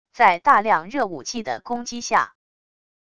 在大量热武器的攻击下wav音频